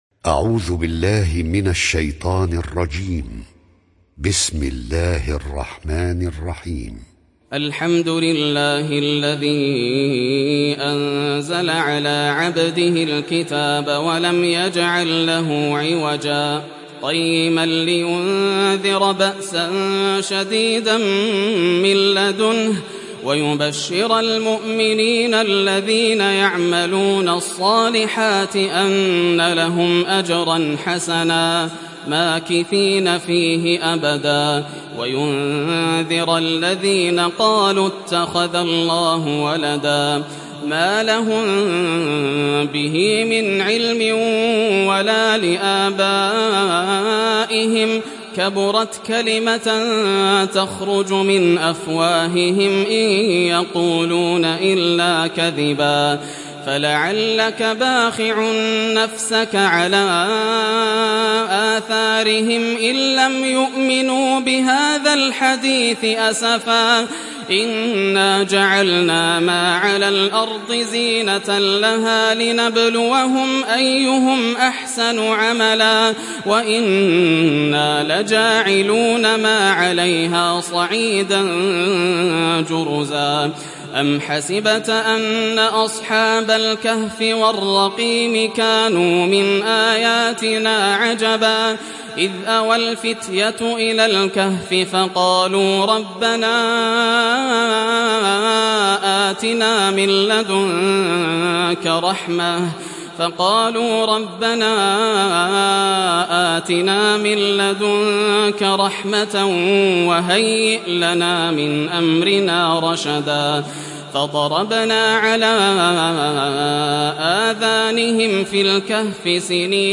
تحميل سورة الكهف mp3 بصوت ياسر الدوسري برواية حفص عن عاصم, تحميل استماع القرآن الكريم على الجوال mp3 كاملا بروابط مباشرة وسريعة